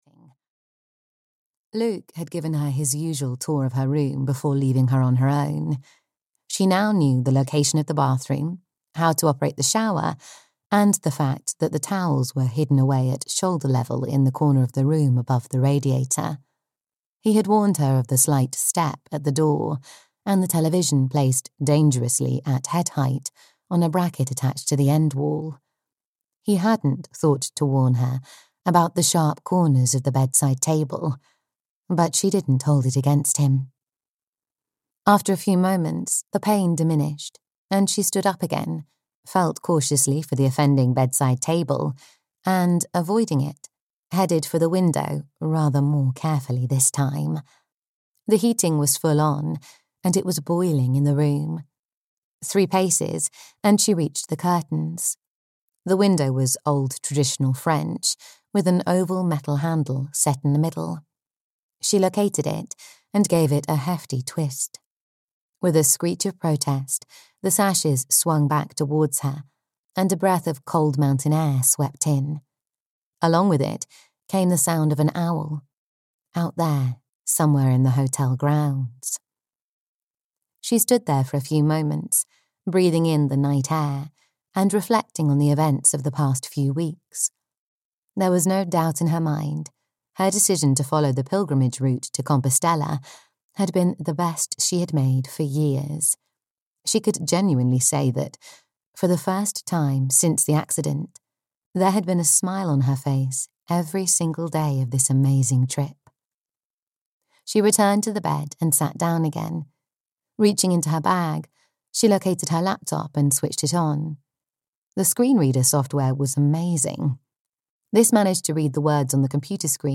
Chasing Shadows (EN) audiokniha
Ukázka z knihy